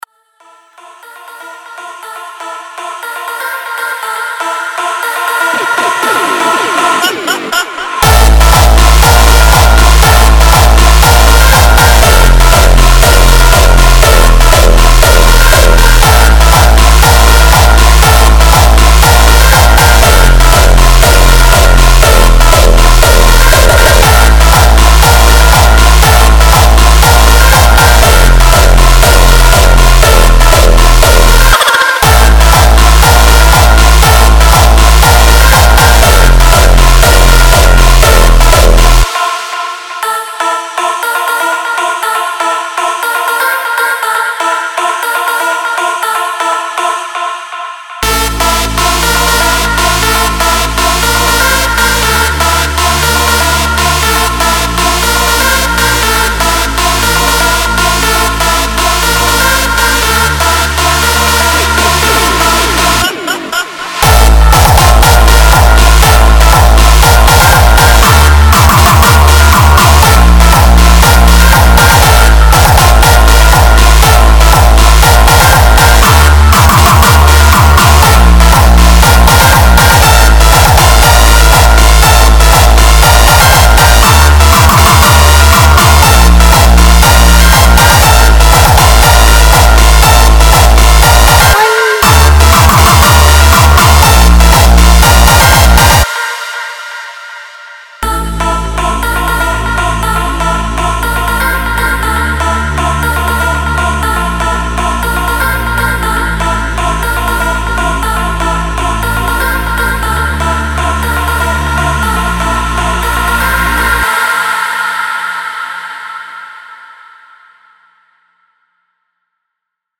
Жанр: Фонк